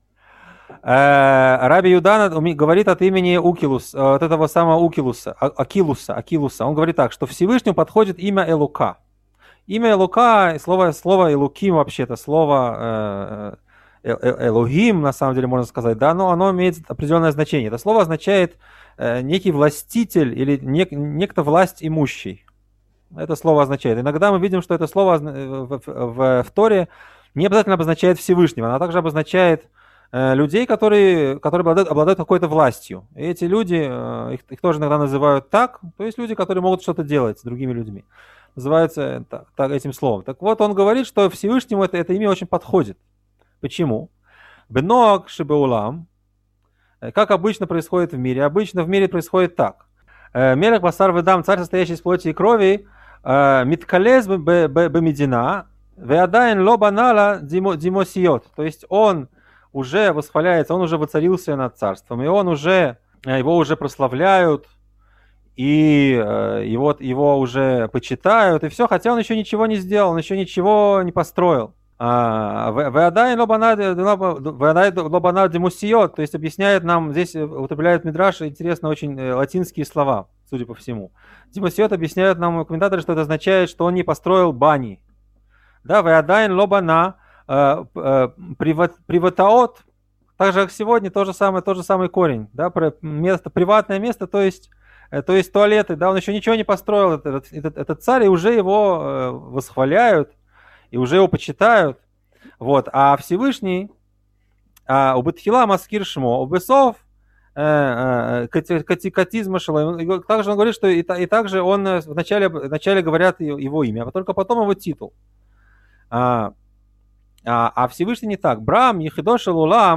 Урок 10 Новые небеса и новая земля